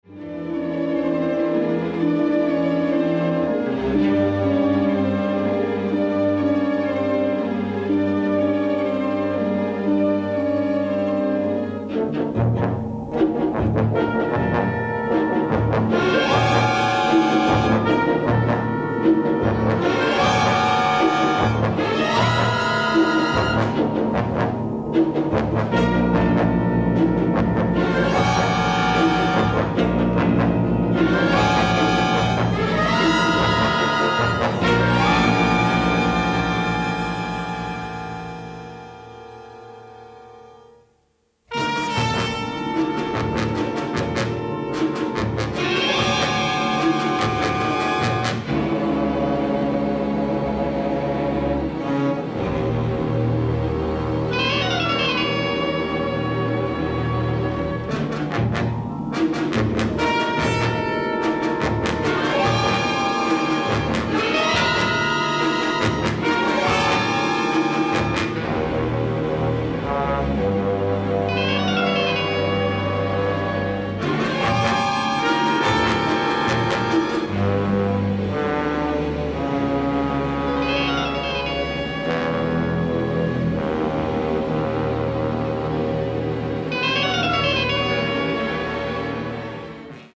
remarkable orchestral score